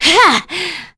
Demia-Vox_Attack1.wav